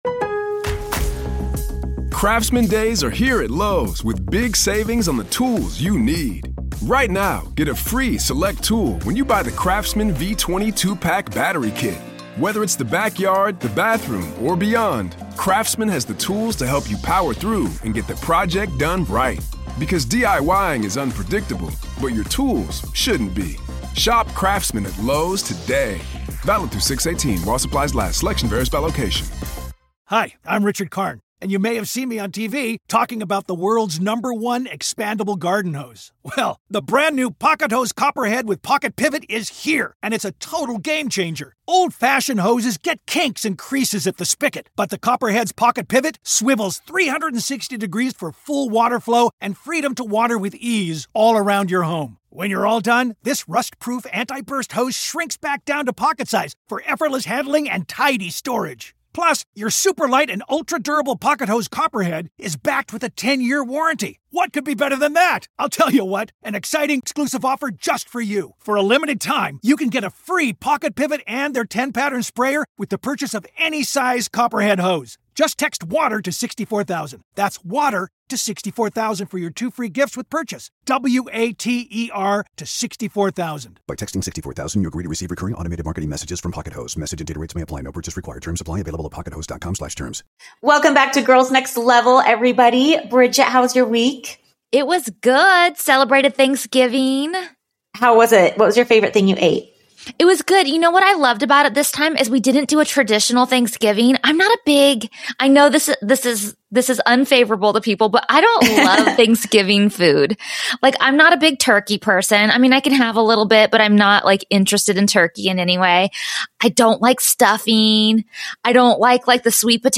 We're bringing you our second ever guest episode starring one of our favorite Playmates of all time, Carmella DeCesare Garcia!